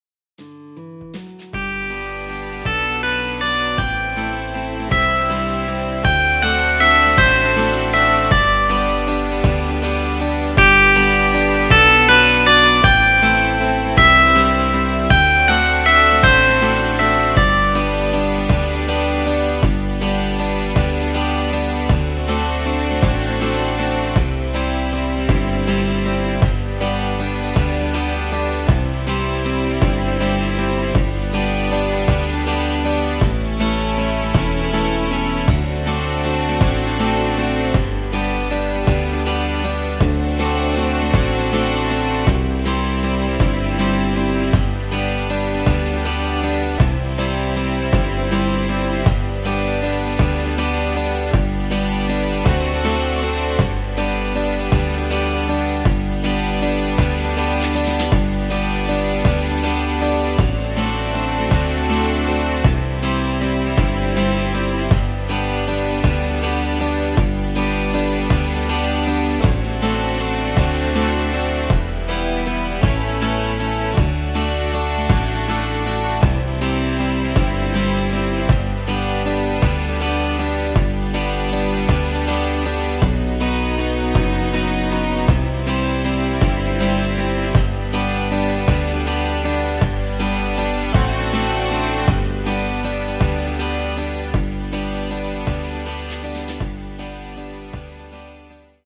• Tonart:  C Dur, G Dur (Originaltonart)
• Art: Playback vollinstrumentiert
• Das Instrumental beinhaltet NICHT die Leadstimme
Klavier / Piano